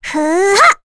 Reina-Vox_Casting5.wav